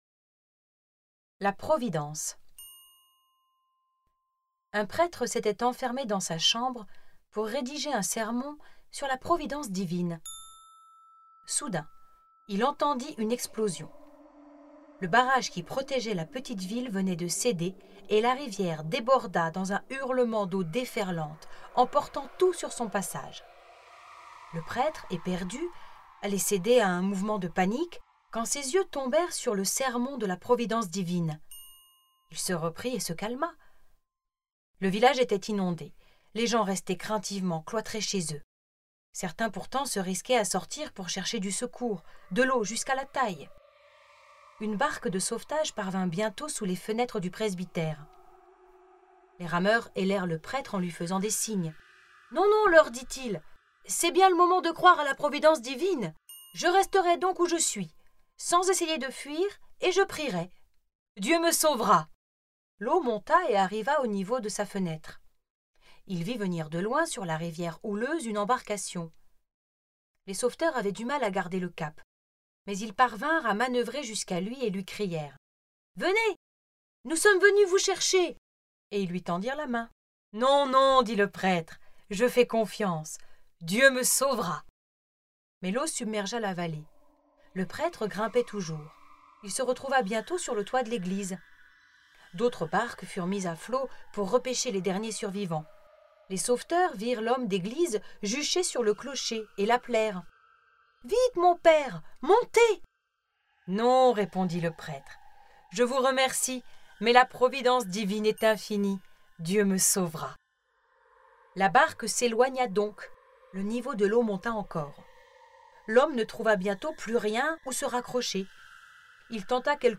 Audiolivres chrétiens